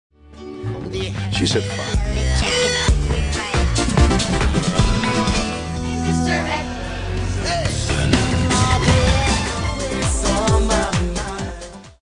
Check out my VERY eclectic mix -->